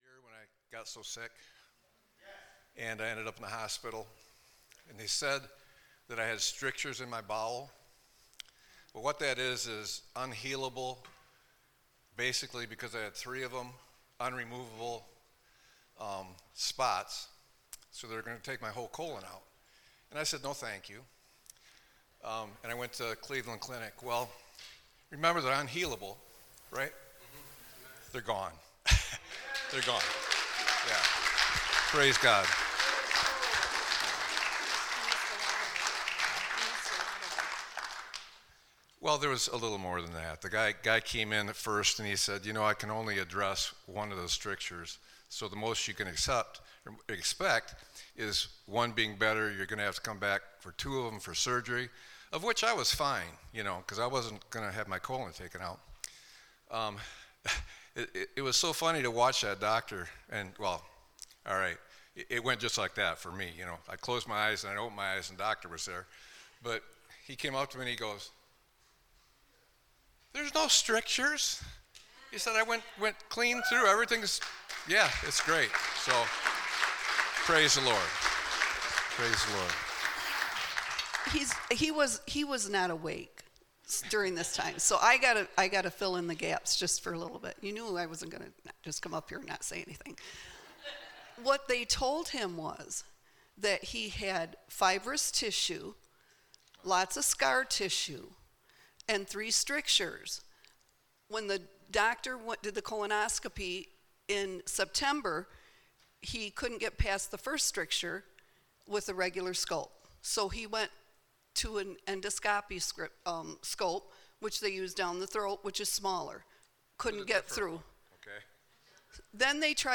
Sermon-8-10-25.mp3